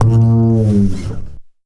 Upright 9 F.wav